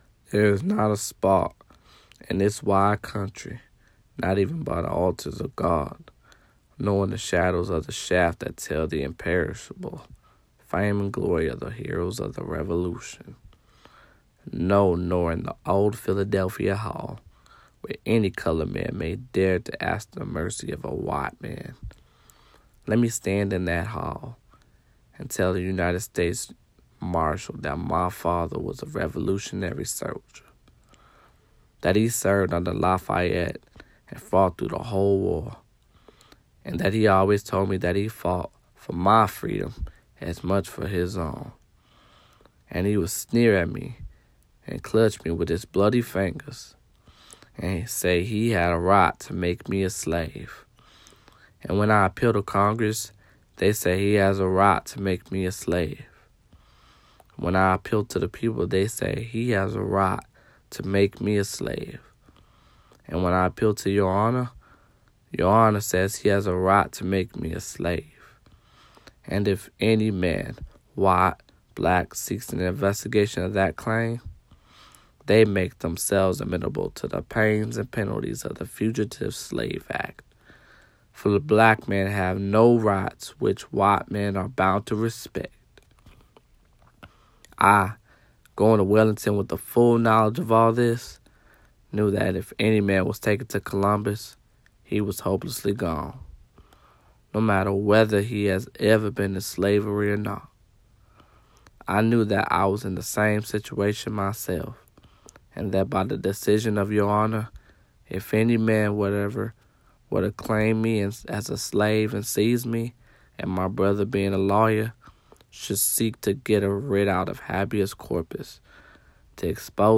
The following audio is a reenactment of part of a speech Langston gave at the Cuyahoga County Courthouse, where he was tried for violating the Fugitive Slave Act.